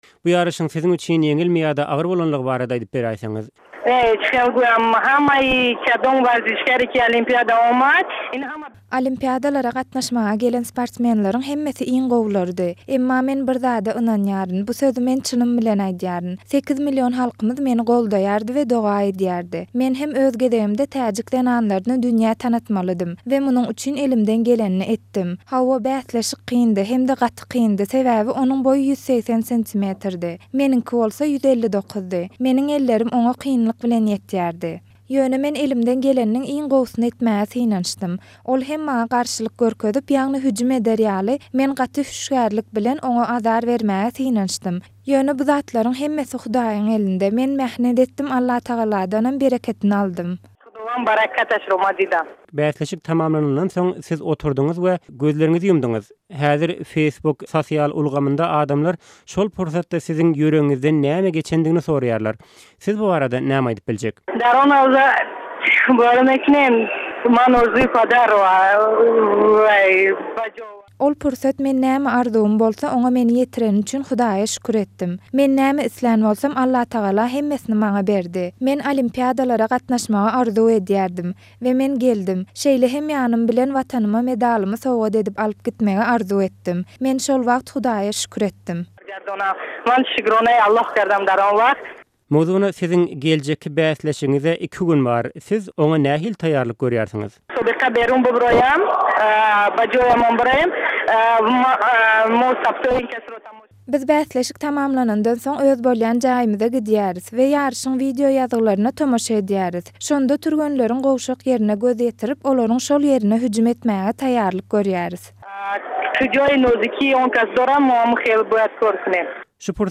Bürünç medala eýe bolan täjik boksçusy bilen söhbet